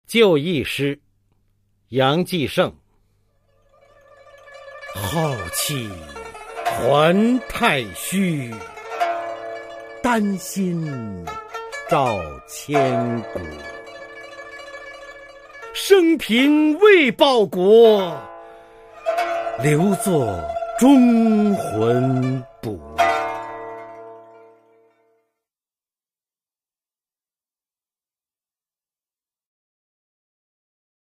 [明代诗词诵读]杨继盛-就义诗 朗诵